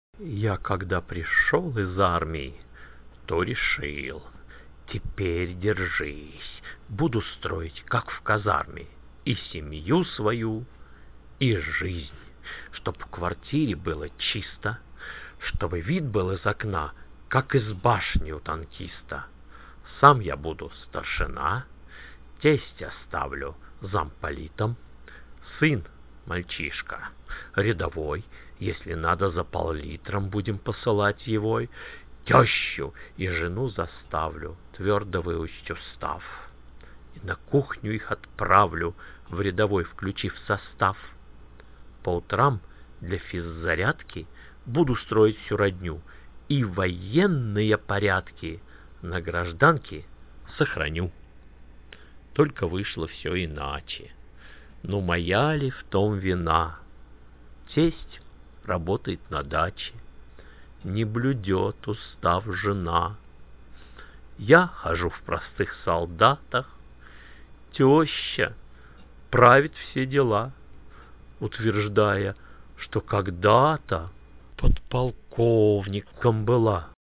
Я загружусь и прочту сам.